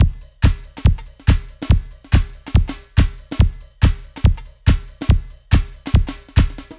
_DR RHYTHM 2.wav